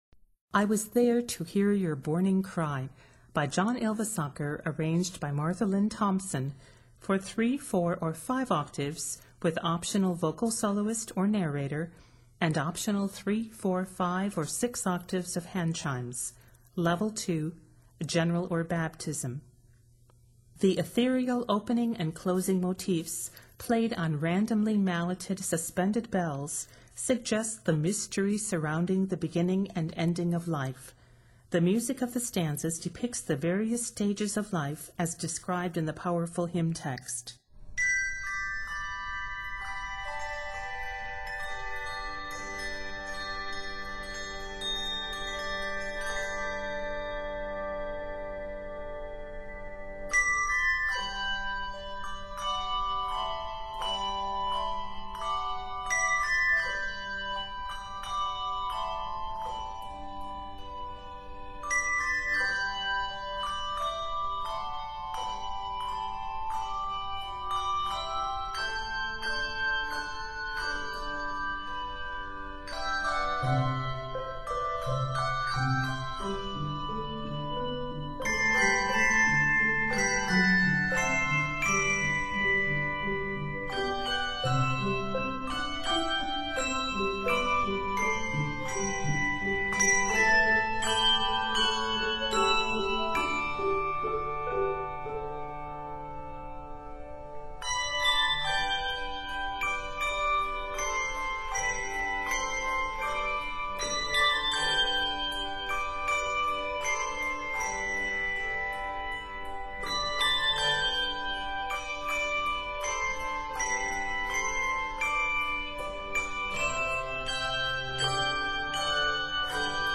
Set in G Major, this piece is 64 measures.